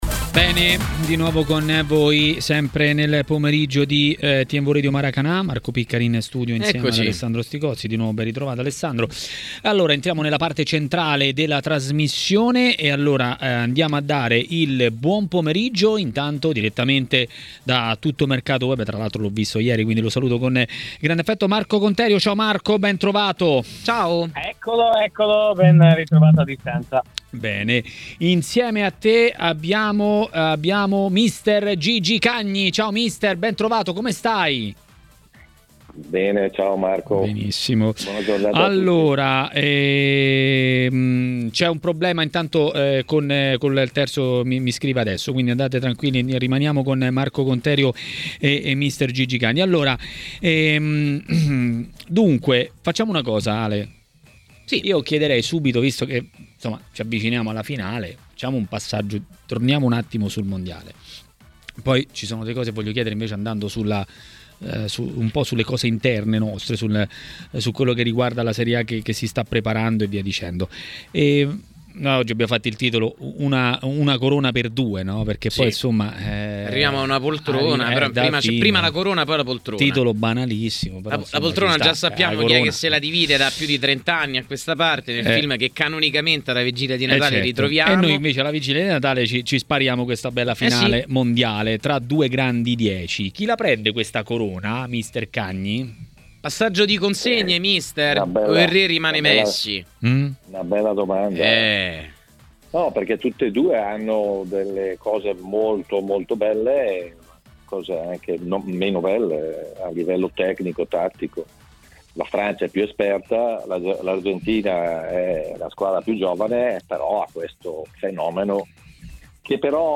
Per parlare di Mondiali e non solo a Tmw Radio, durante 'Maracanà', è intervenuto mister Gigi Cagni.